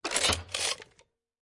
BRA1: 会计师 " Check Press STAMP Slow 07
描述：几个美味的复古添加＆amp;会计机器。这是早期现代簿记的最佳声音。
标签： 计费 机械的 机械 机器 按钮 葡萄酒 办公室 弗利
声道立体声